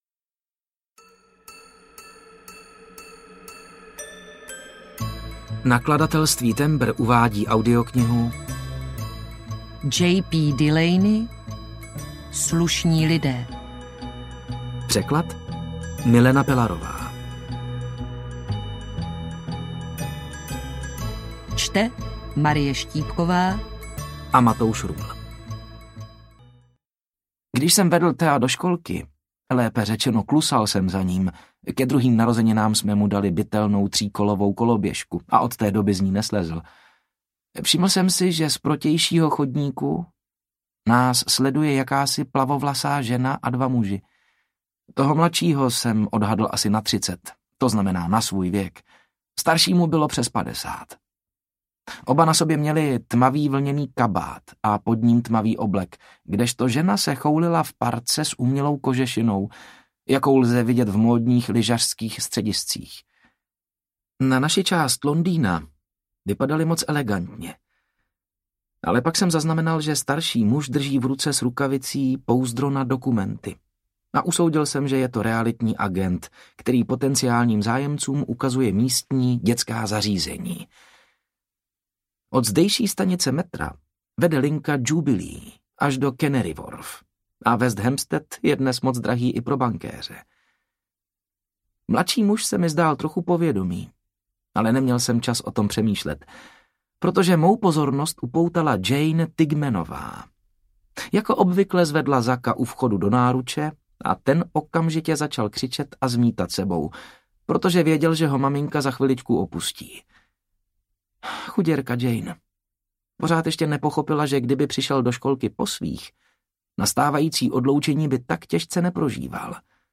Slušní lidé audiokniha
Ukázka z knihy
slusni-lide-audiokniha